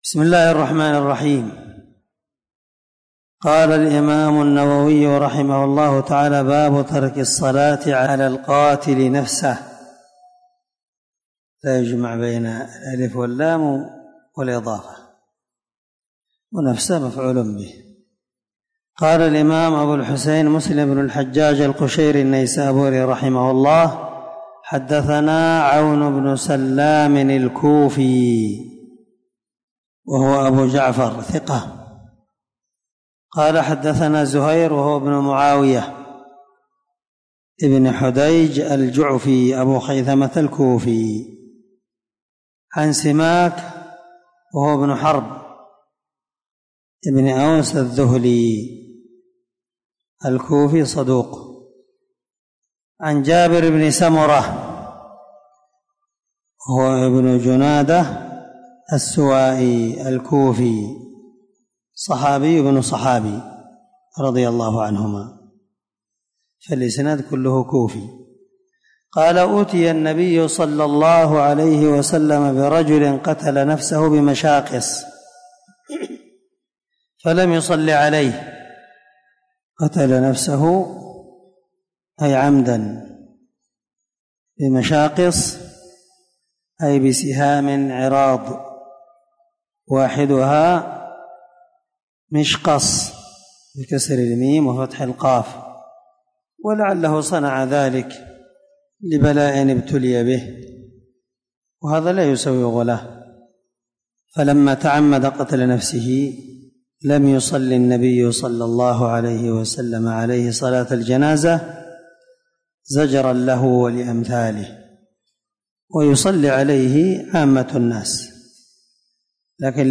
• سلسلة_الدروس_العلمية
• ✒ دار الحديث- المَحاوِلة- الصبيحة.